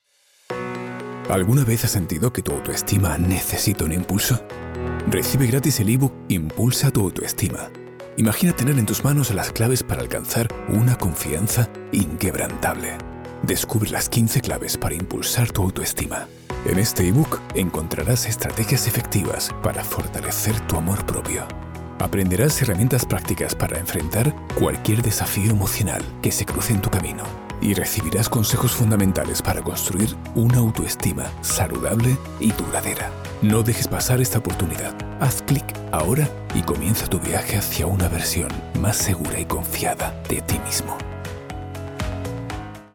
Ich kann mit englischem und andalusischem Akzent sprechen.
BaritonTiefNiedrig